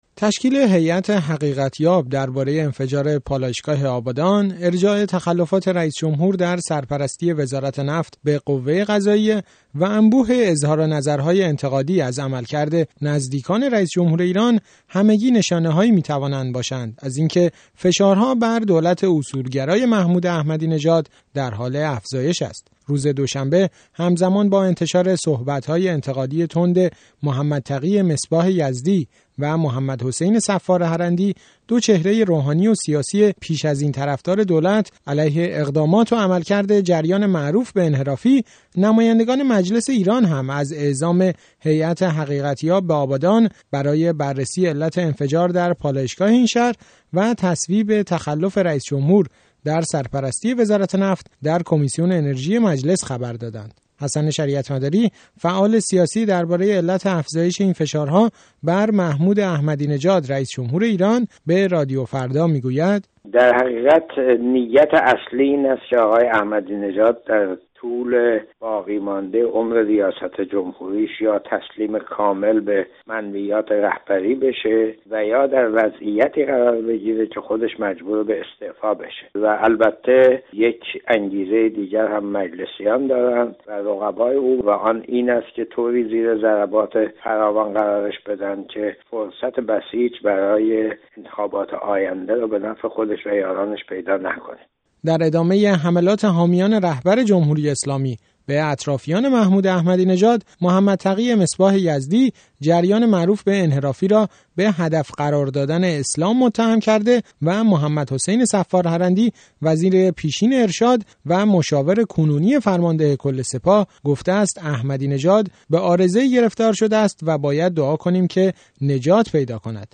گزارش رادیویی
گفت وگو